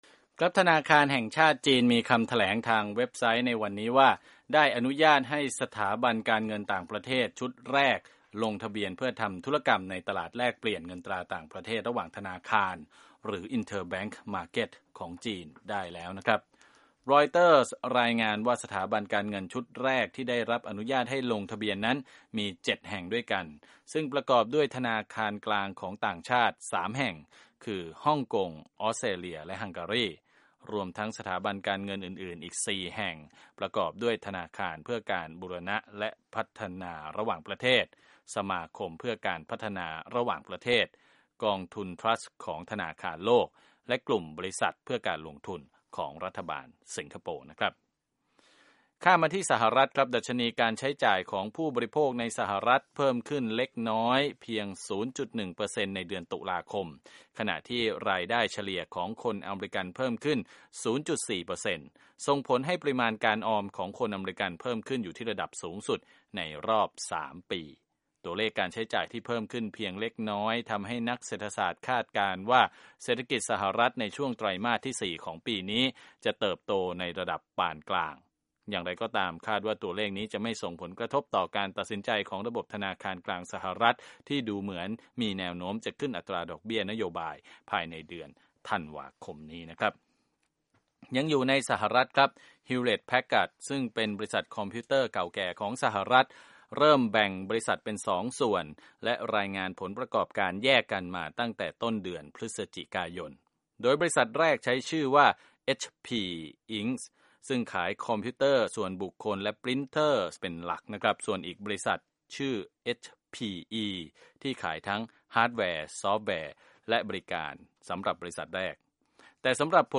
ธุรกิจ
รวมข่าวธุรกิจ 11/25/2015